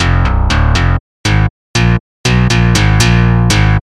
T 弱化低音
描述：用buzz制作的4条长循环。有一些非常低的频率存在，所以使用hipass滤波器。
标签： 低音 丛林 循环 合成器
声道立体声